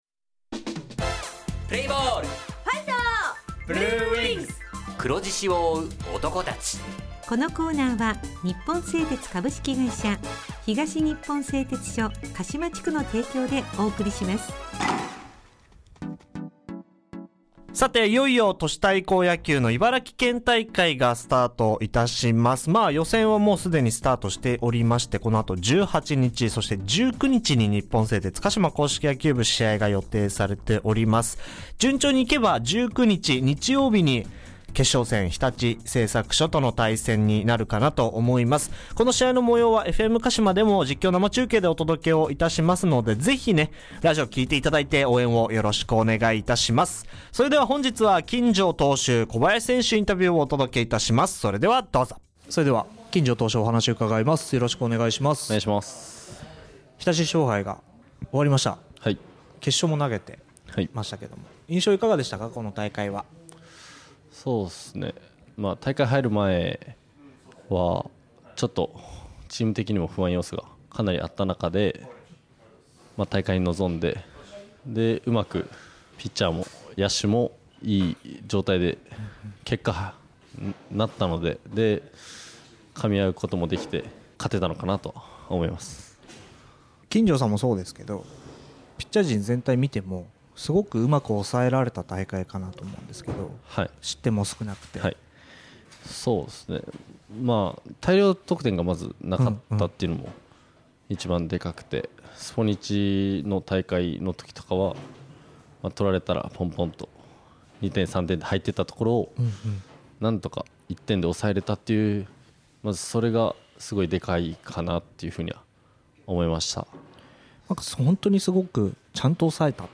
選手インタビュー
地元ＦＭ放送局「エフエムかしま」にて鹿島硬式野球部の番組放送しています。